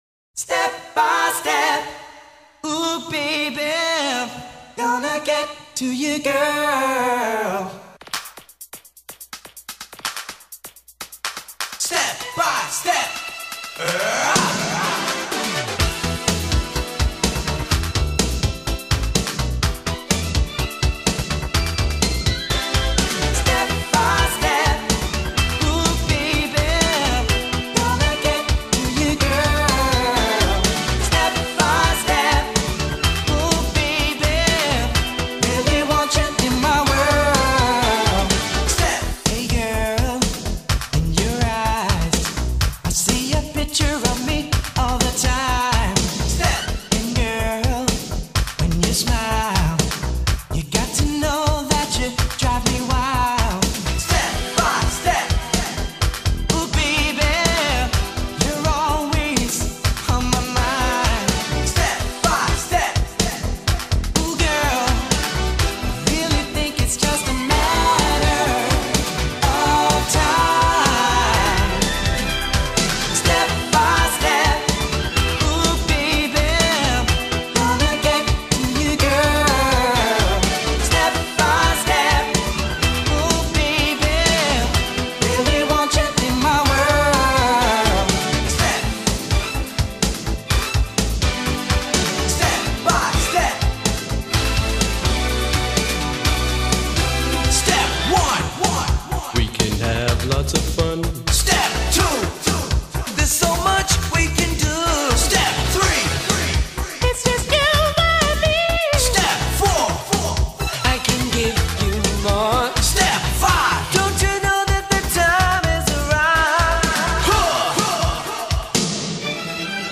BPM125-125
Audio QualityMusic Cut